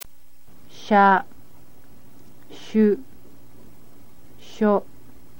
click on any of a group to hear the group spoken